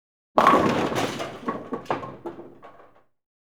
Bowling.wav